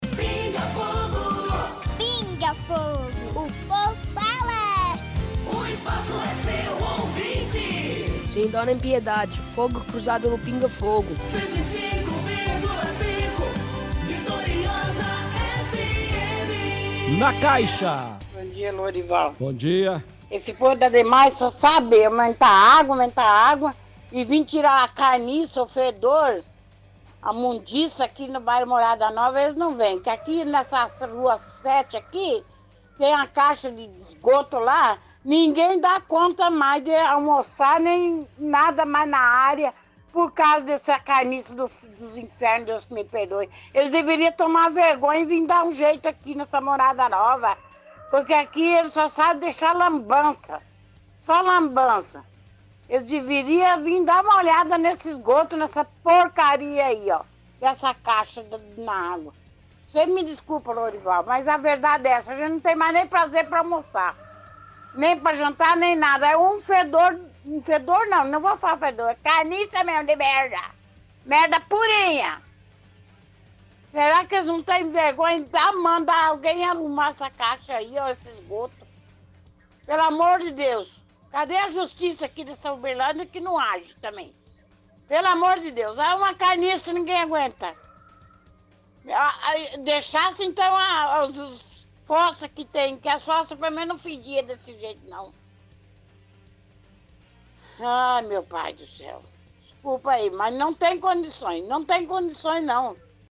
– Ouvinte reclama do aumento da conta de água pelo Dmae.